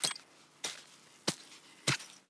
SFX_Hacke_01.wav